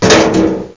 klonk5.mp3